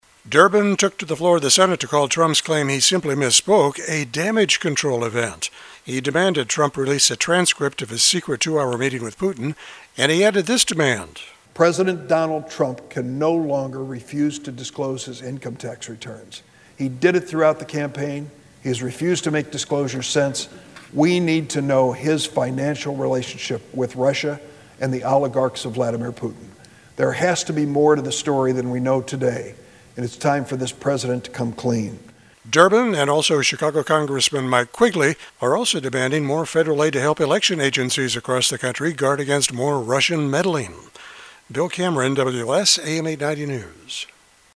Durbin took to the floor of the senate to call Trump’s claim he misspoke “a damage control event.”